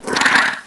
Heroes3_-_Infernal_Troglodyte_-_HurtSound.ogg